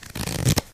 cardFan1.ogg